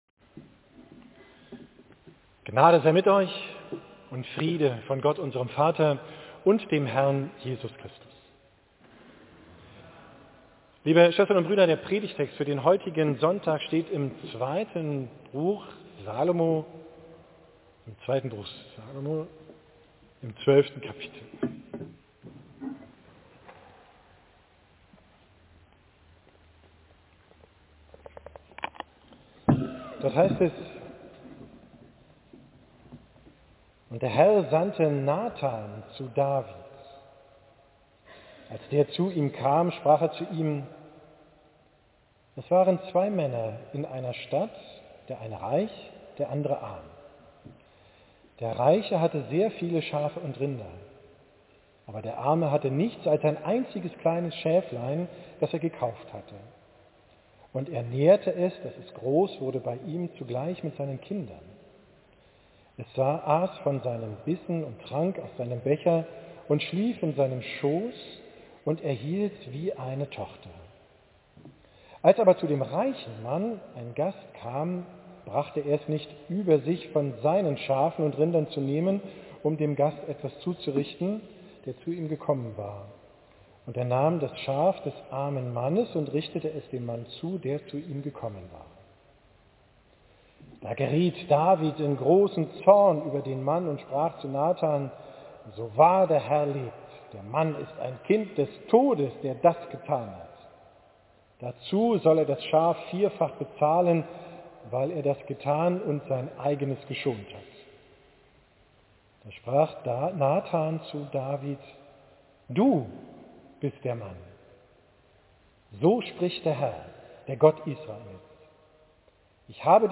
Predigt vom 11.